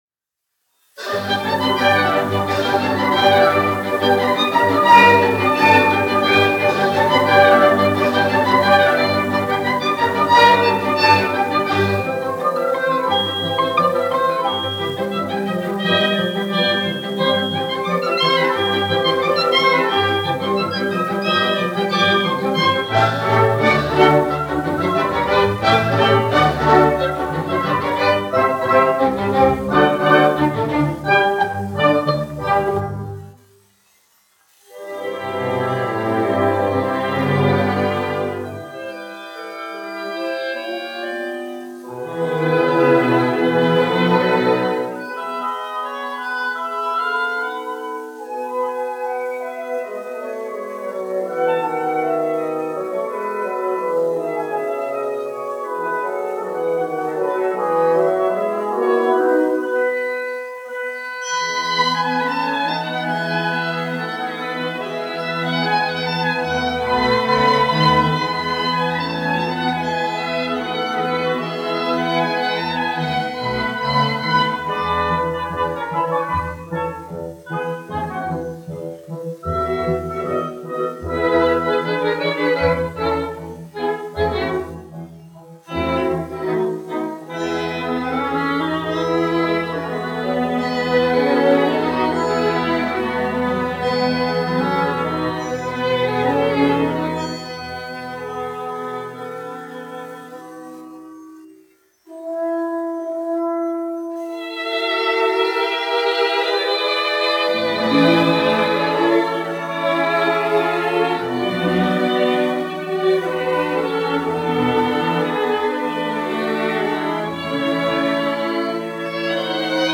1 skpl. : analogs, 78 apgr/min, mono ; 25 cm
Uvertīras
Operetes--Fragmenti